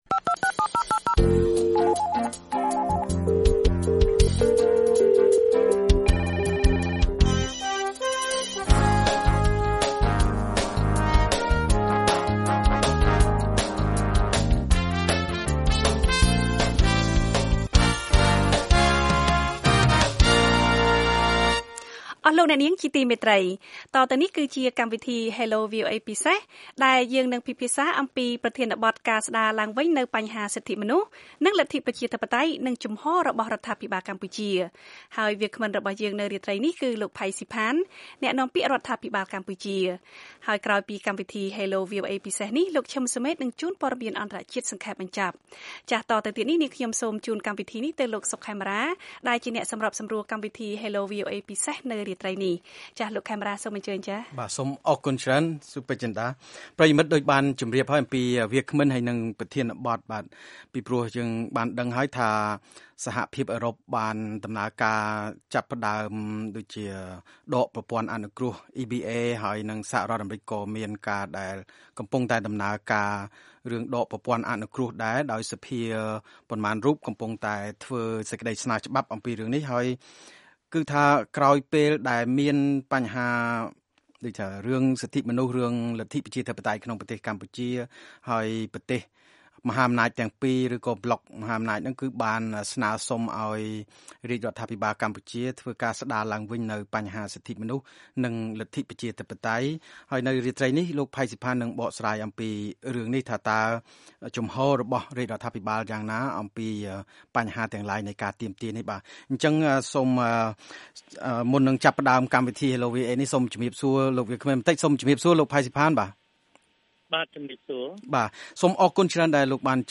លោក ផៃ ស៊ីផាន អ្នកនាំពាក្យរបស់រដ្ឋាភិបាលបកស្រាយអំពីបញ្ហាសិទ្ធិមនុស្សនិងលទ្ធិប្រជាធិបតេយ្យ នៅពេលសហភាពអឺរ៉ុបនិងអាមេរិកនិងអង្គការសង្គមស៊ីវិលទាមទារឲ្យមានការស្តារឡើងវិញ។